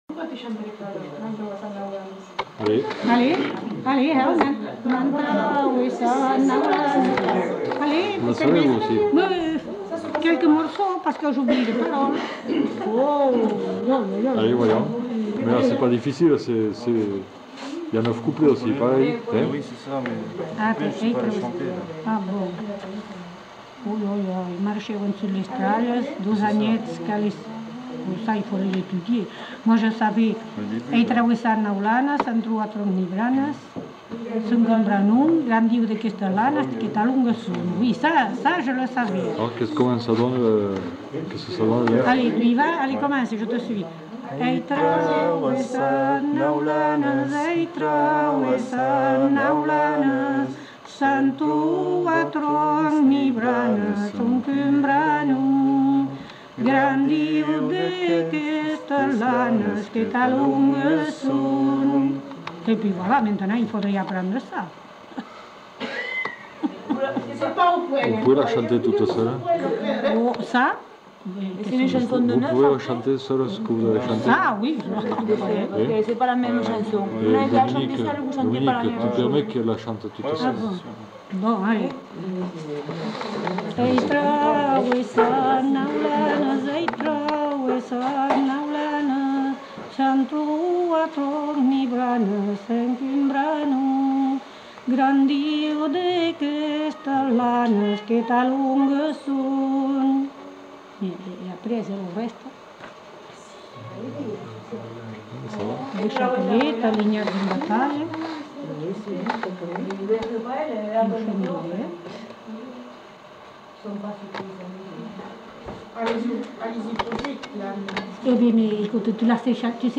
Lieu : Allons
Genre : chant
Effectif : 1
Type de voix : voix de femme
Production du son : chanté
Classification : chansons de neuf